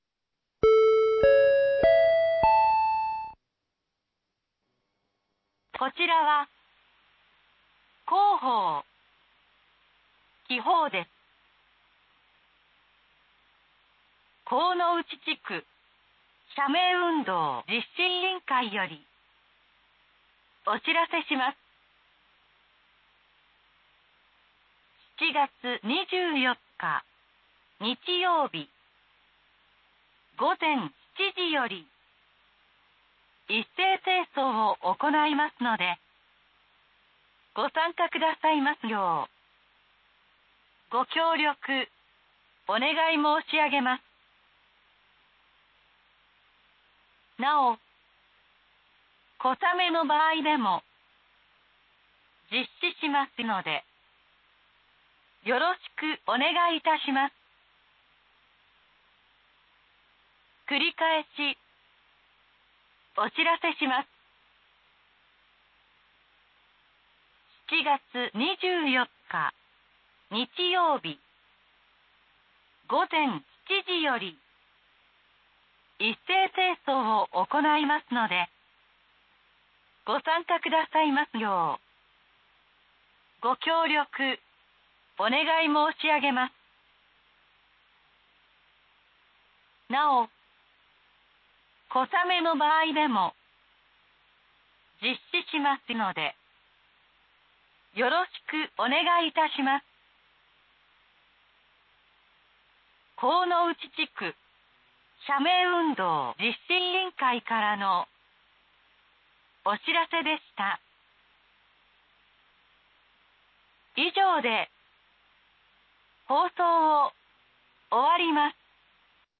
※神内地区のみ放送
放送音声